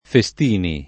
[ fe S t & ni ]